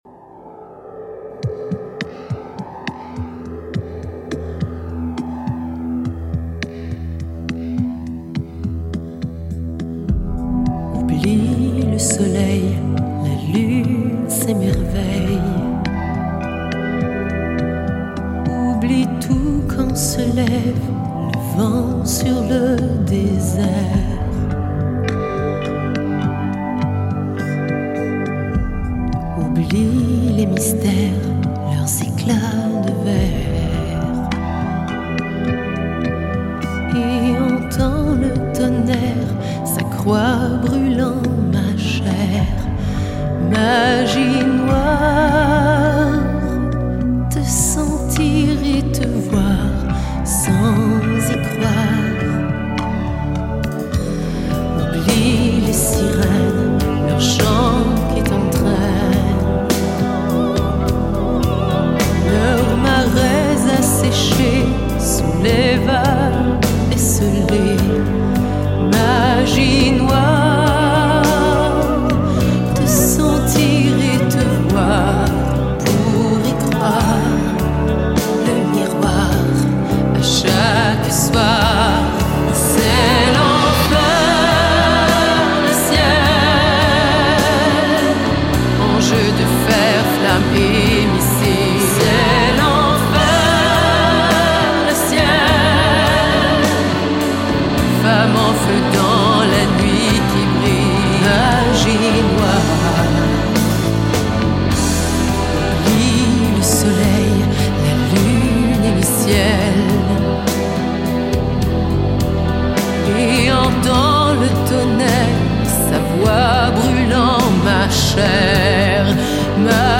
橫跨流行古典、加拿大英法語雙聲跨界音樂新天后
上帝賜予水晶般清澄透澈嗓音獨樹一格
● 這張結合聲樂美學，回歸新世紀空靈，更勝流行音樂質感的誠意專輯，是眾多商業包裝中難得一見清新佳作。